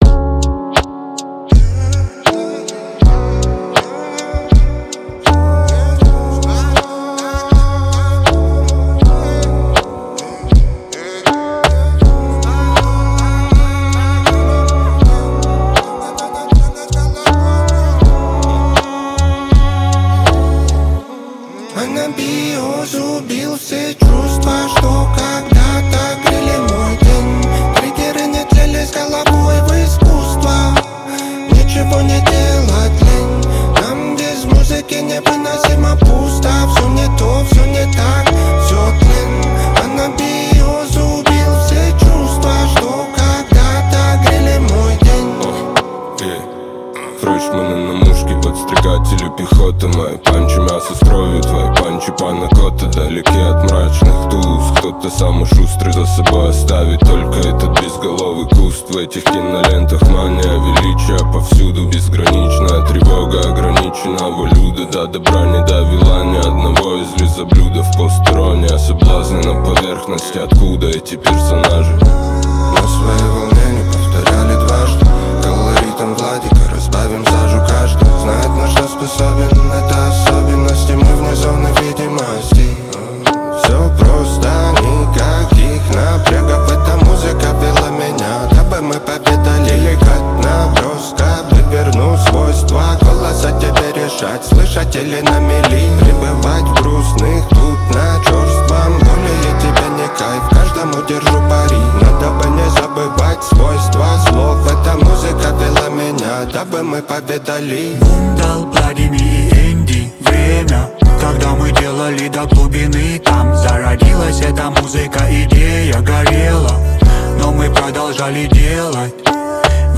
Трек размещён в разделе Русские песни / Рок.